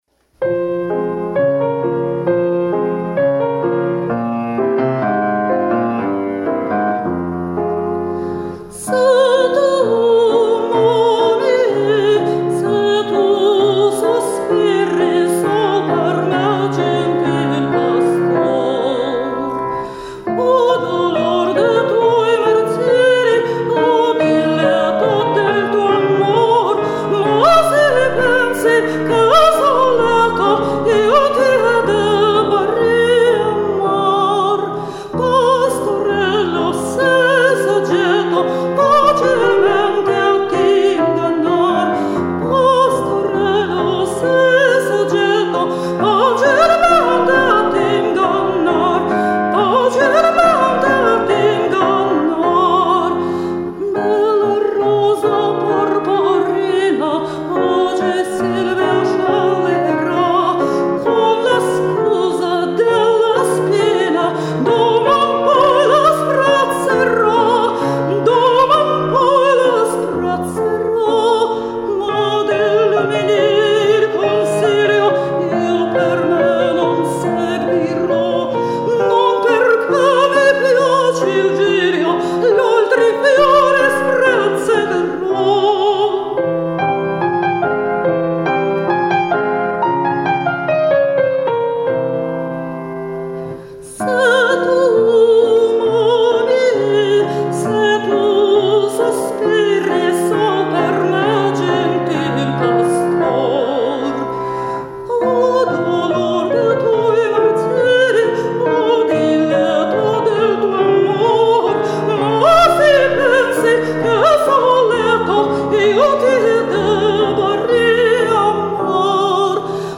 сопрано и меццо-сопрано - от учениц до великих
Комментарий соперника: Поскольку это никак не опера, а всего лишь камерная ария (для малых залов), рискну принять вызов.